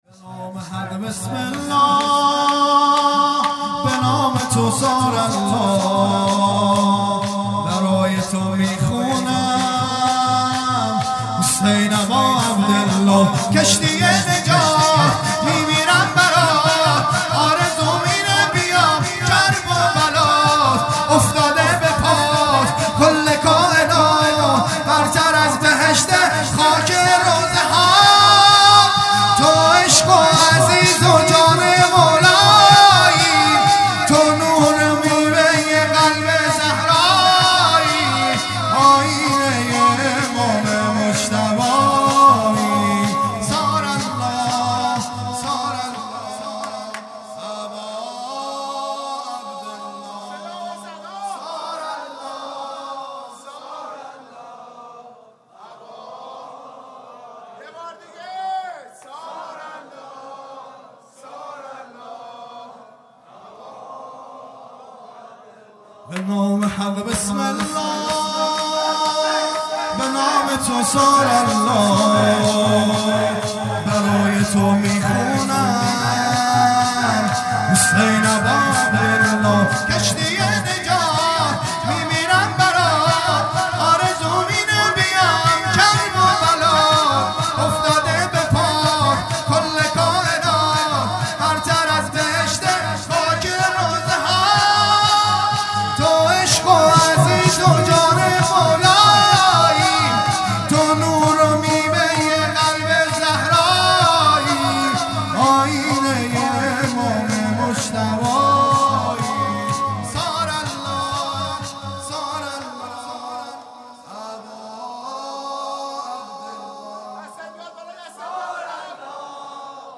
هیئت دانشجویی فاطمیون دانشگاه یزد
وفات حضرت معصومه (س) | ۲۷ آذر ۹۷